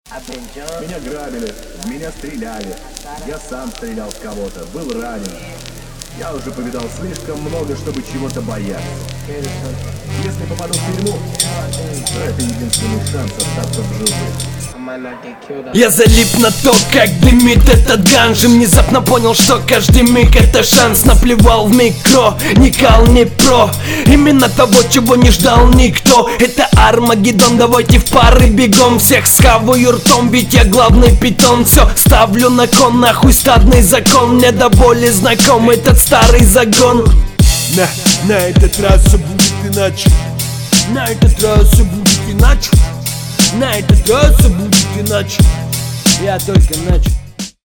Ну, подача нормуль, но вообще трек средний.